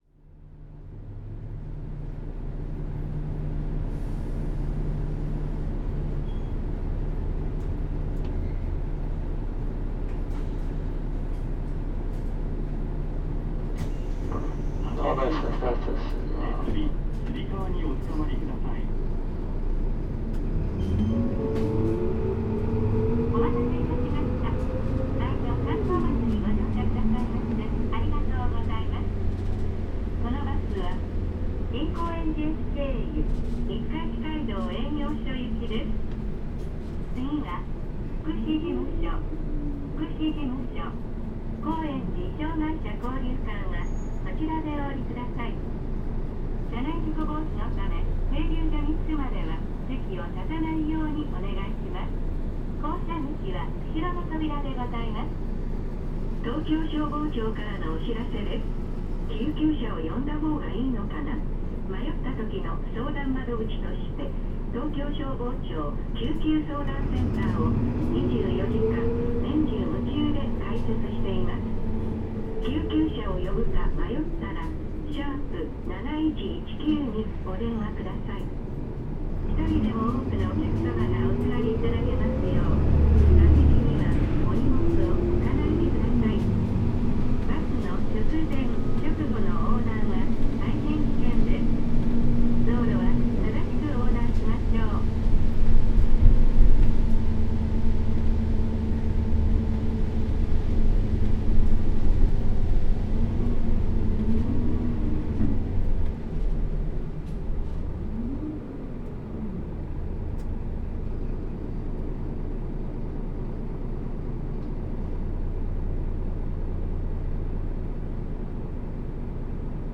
関東バス 日産ディーゼル KL-UA452KAN改 ・ 走行音(全区間) (28.4MB*) 収録区間：中35系統 五日市街道営業所→中野駅 関東バスの主力車として活躍するKL-UA。